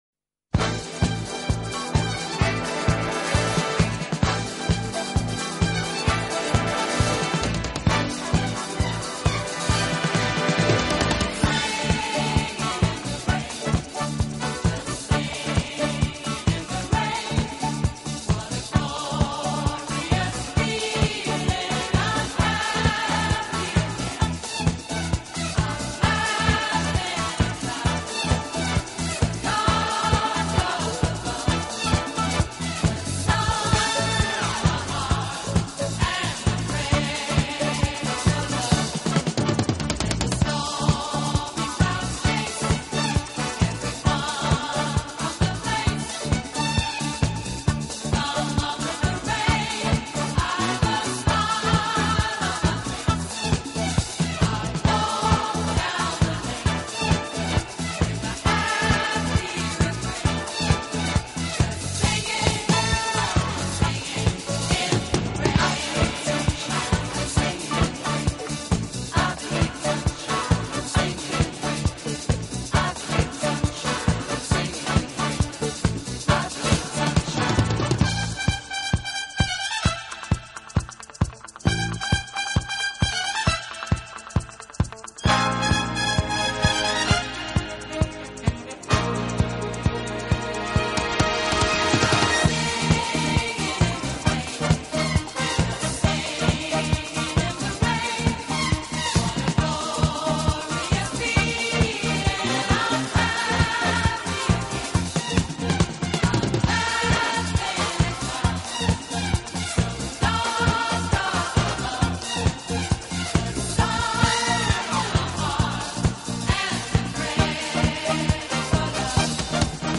顶级轻音乐